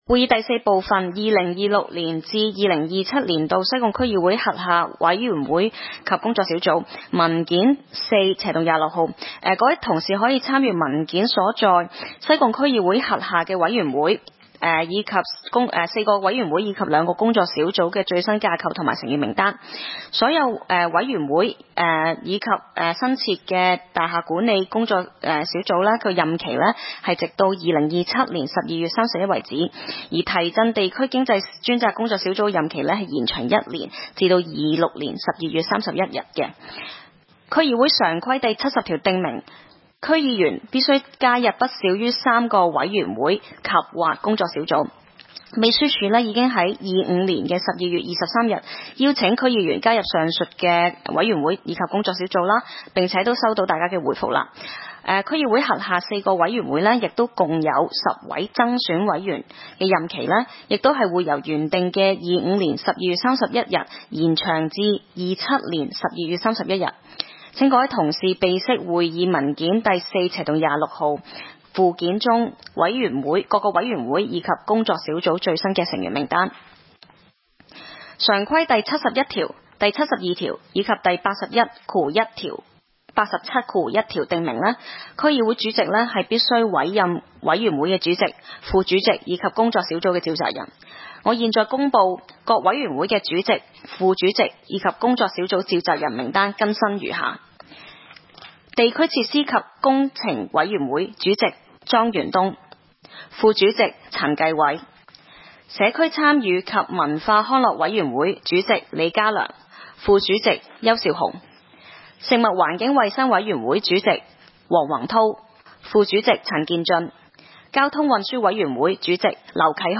區議會大會的錄音記錄
西貢區議會第一次會議
西貢將軍澳政府綜合大樓三樓